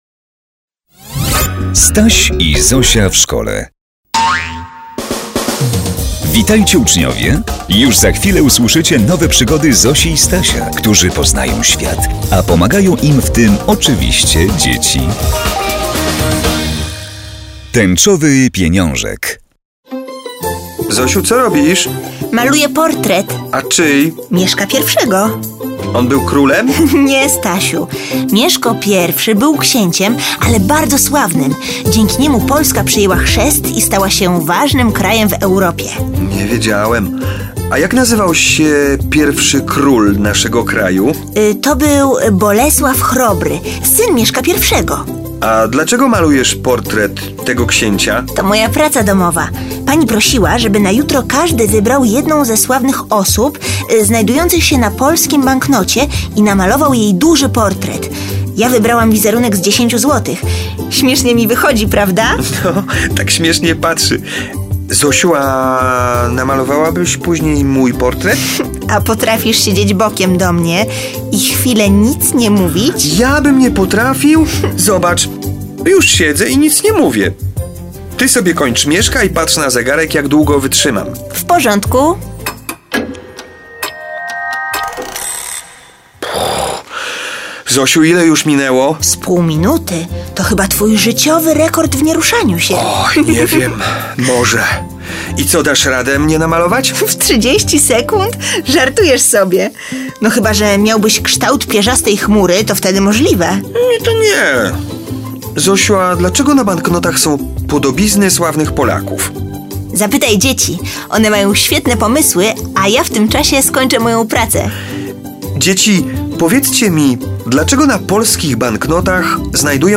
Informacje o pliku Nazwa: klasa_II_1 Tęczowy pieniążek Waga: 7,60MB Typ (MIME): mp3 (audio/mpeg) Pobierz plik Wybrany plik powiązany jest ze stronami: Słuchowiska dla klas I - III - Staś i Zosia w szkole